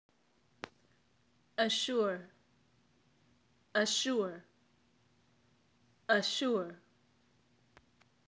Read on for definitions, example sentences, and mp3s for pronunciation of three easily confused English verbs: assure, insure, and ensure.
(Listen to my pronunciation of “assure” above!)